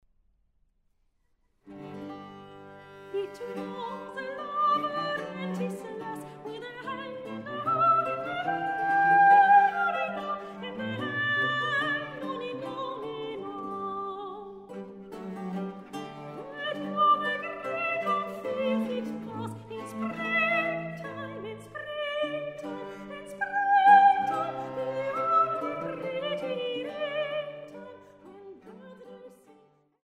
Englische Liebeslieder aus drei Jahrhunderten